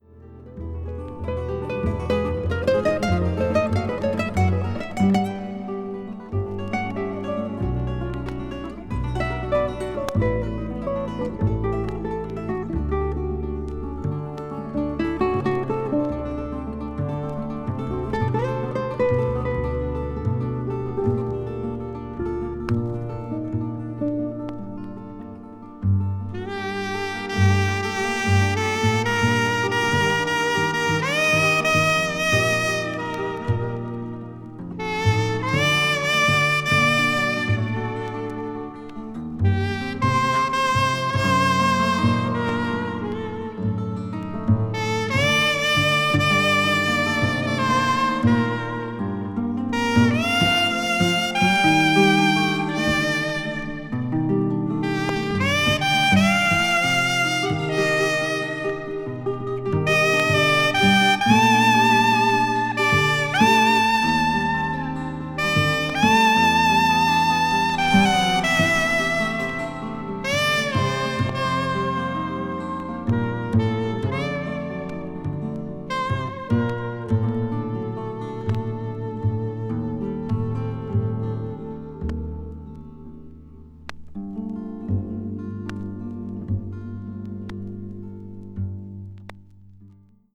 北欧の深い森のような情景が描かれた美しい音楽で、奏でられるすべての音がじんわりと染みてくるとても静かな世界。
A2後半から中盤にかけて1cm程のスリキズがあり、プチノイズが入る箇所があります。
contemporary jazz   ethnic jazz   spiritual jazz